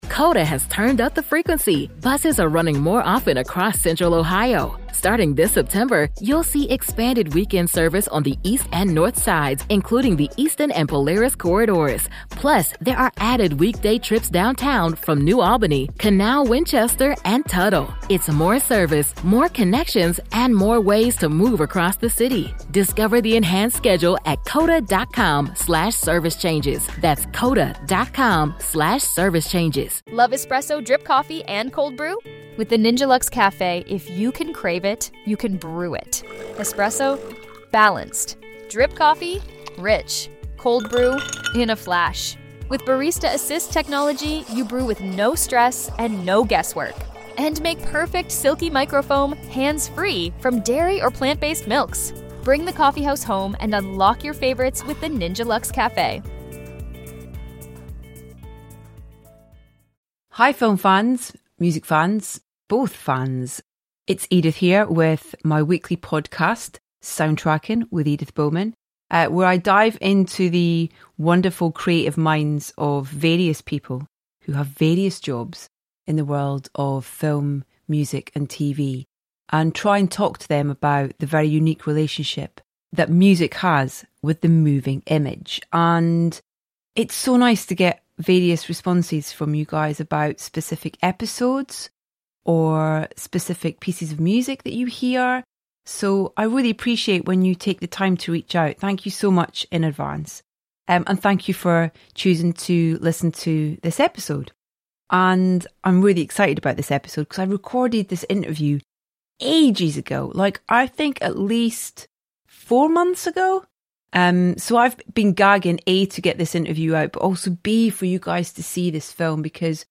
We have a proper legend for you on our latest episode of Soundtracking in the shape of the great Sir Kenneth Branagh, who also happens to be one of the loveliest people you could ever wish to interview.